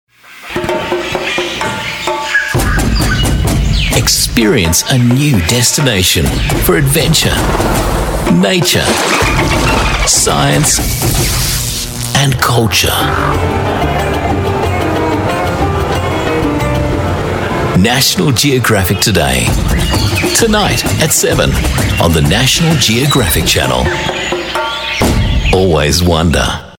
AU ENGLISH